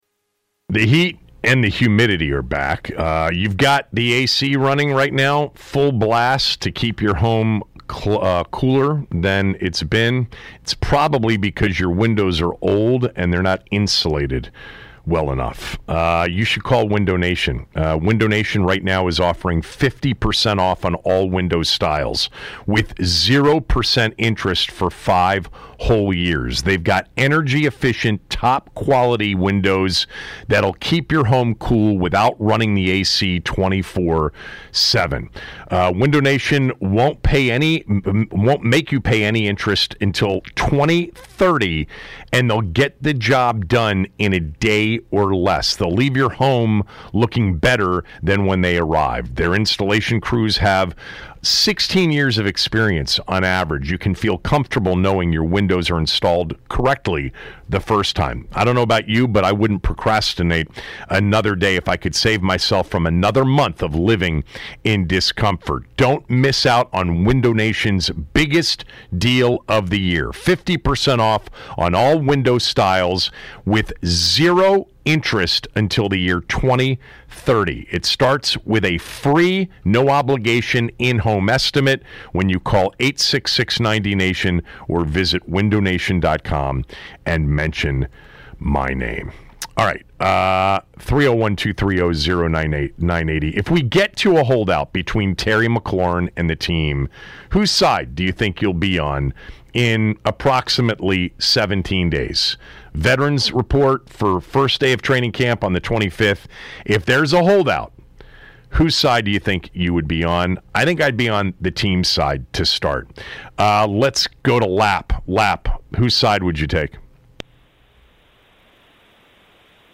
Callers give their opinion on whether they would side with the Commanders or Terry McLaurin if he were to hold out for a new contract.